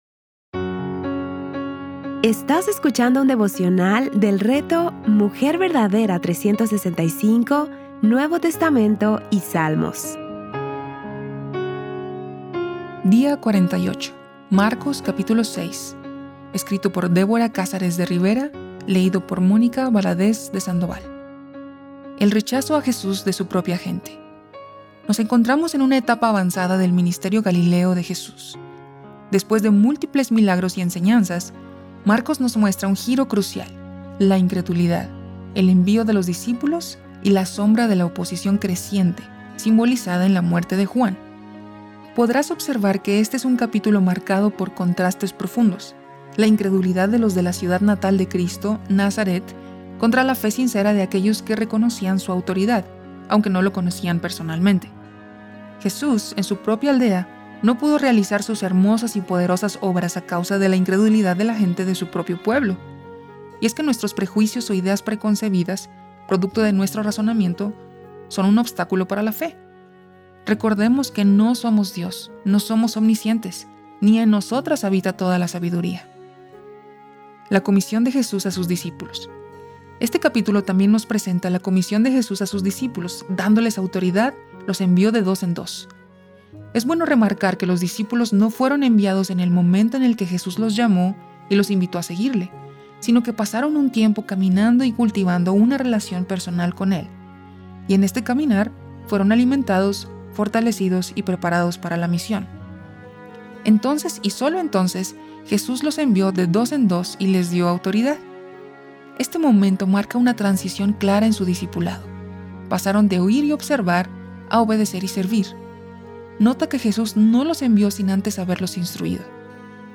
Series:  Marcos y Salmos | Temas: Lectura Bíblica